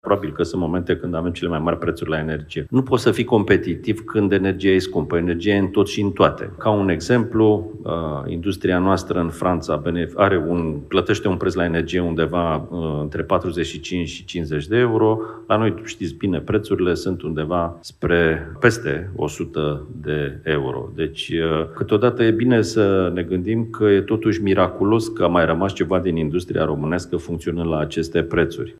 în cadrul forumului „InvestEnergy”